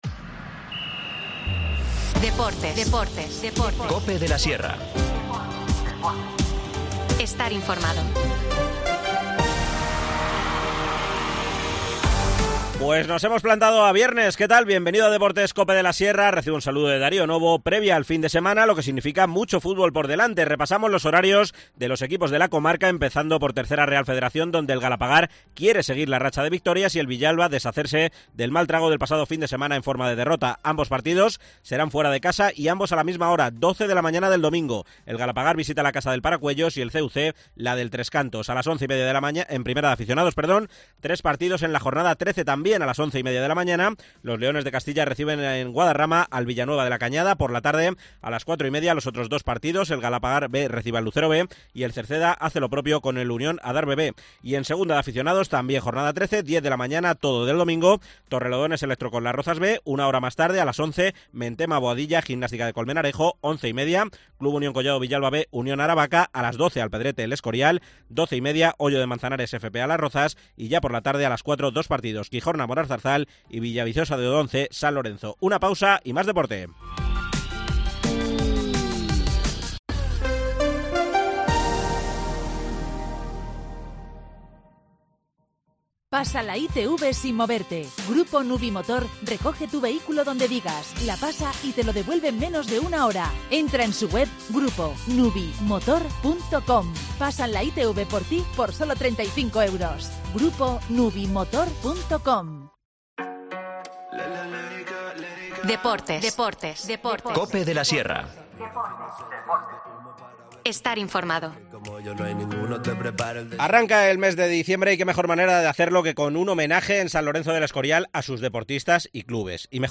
San Lorenzo de El Escorial celebra hoy la edición número 23 de la Gala del Deporte. Clubes y deportistas destacados en sus respectivas competiciones serán galardonados en un acto que comenzará a las 19:00 de la tarde en el Real Colegio Universitario María Cristina. Nos lo cuenta el concejal de Deportes gurriato, Jesús Muñoz.